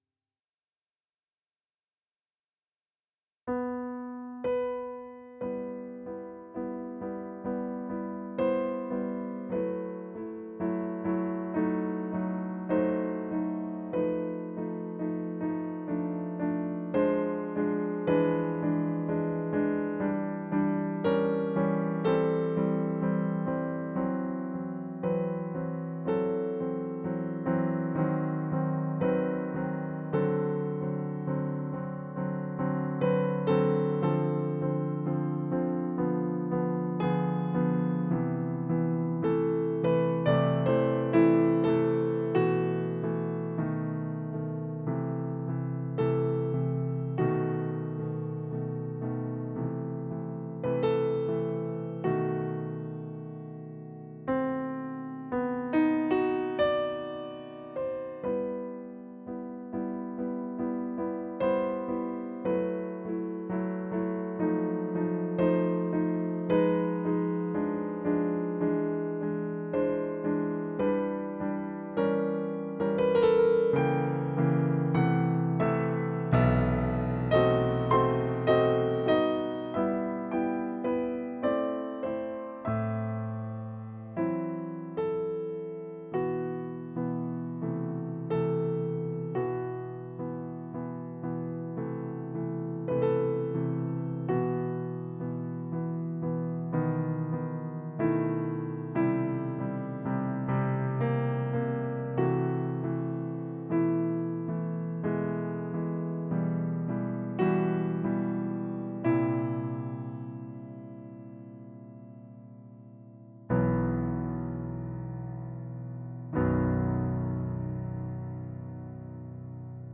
Prelude in Em, Op. 28 Nr. 4 (Piano, Chopin)
Yamaha P-125